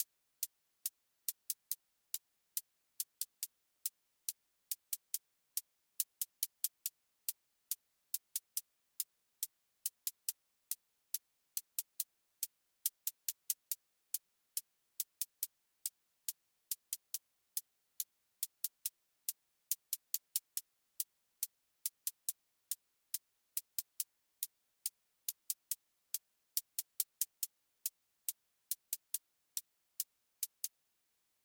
Trap 808 tension with clipped hats